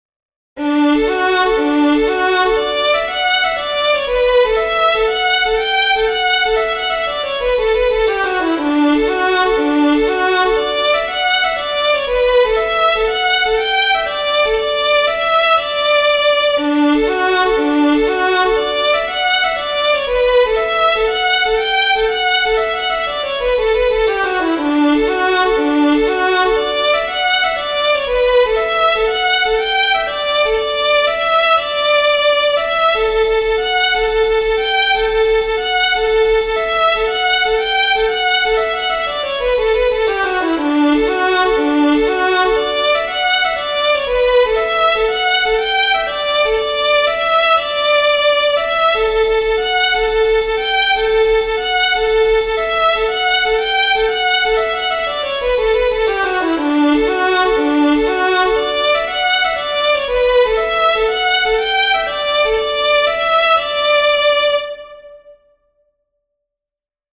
looking for comments on the scored hornpipe structure...
My arrangement is A A B B - I swear I've head A B A B somewhere - no matter
I've tried to emphasize beats 1 and 3
Attached is my scored version as pdf, and an mp3 from the synth to give a feel of what it should sound like.
No special articulations or embellishments in this version ( I'm working on that still - I "hear it in my head" but I can't quite play it, let alone represent it in written form !)